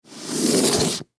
Звук: сдвинули коробку с подарком к имениннику